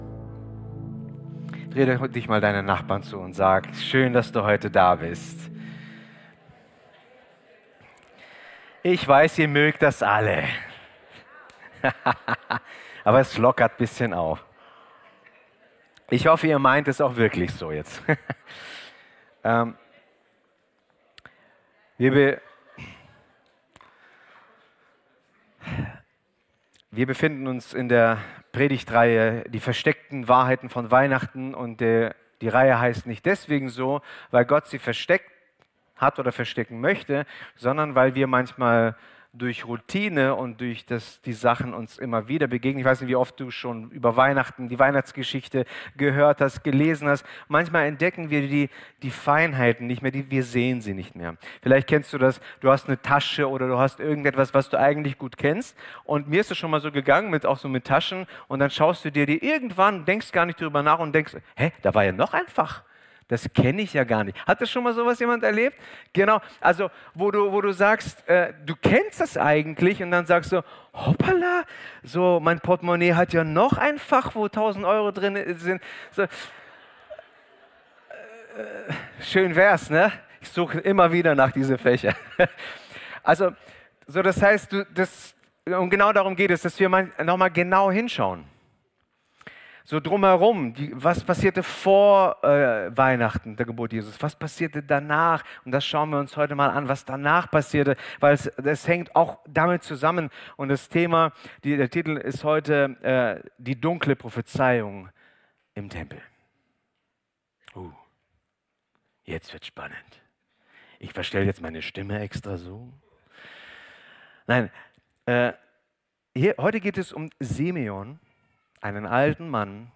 Sonntagspredigten